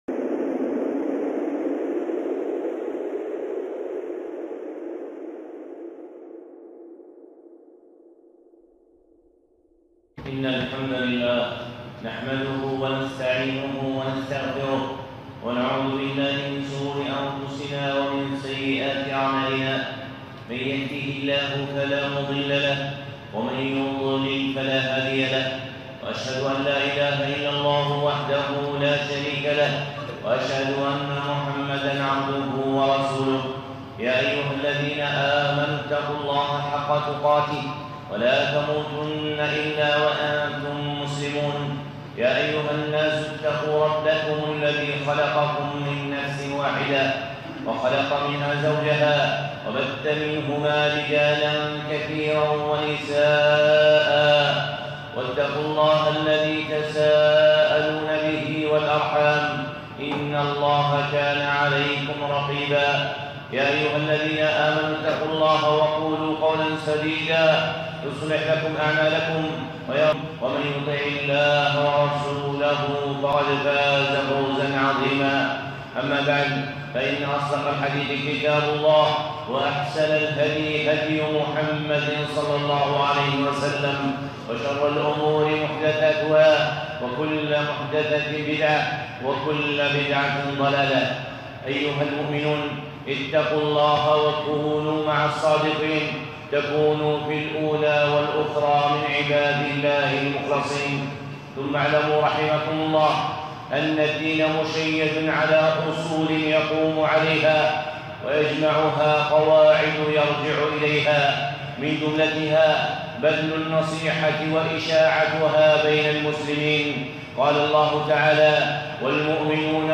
خطبة (عروة النصيحة)